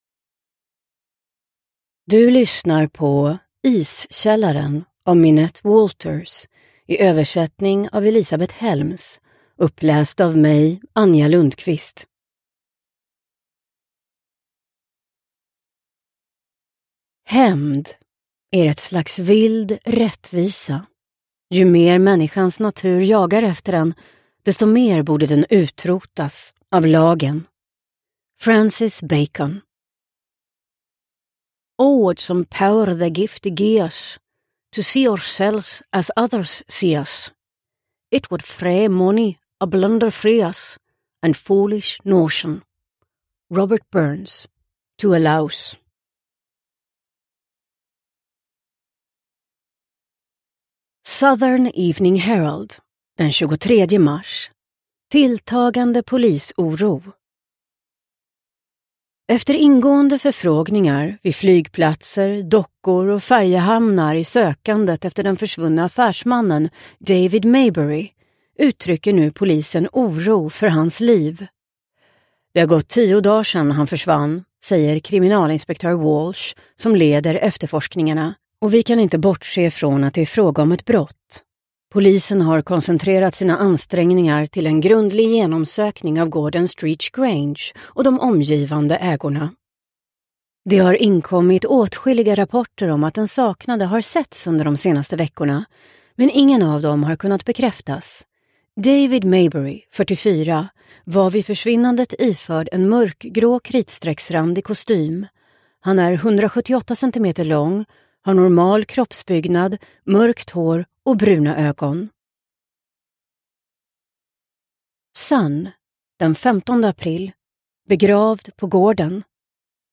Iskällaren – Ljudbok – Laddas ner